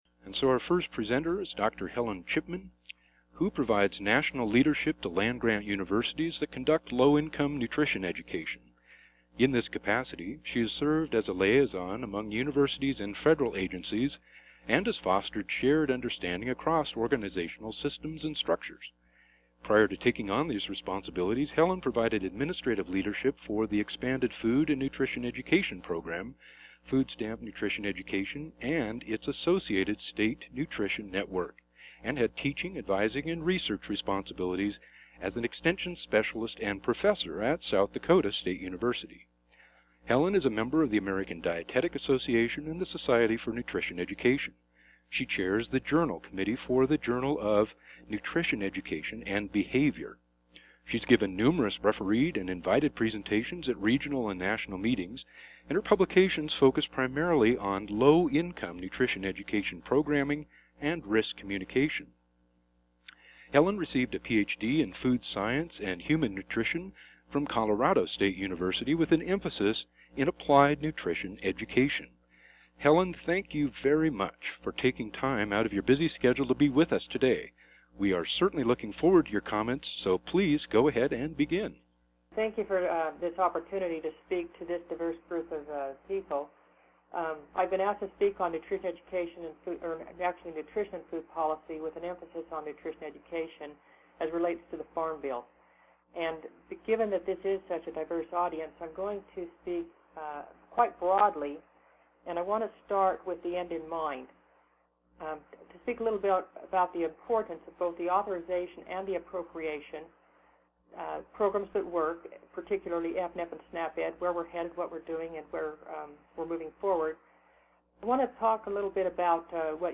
Sections for this webinar include: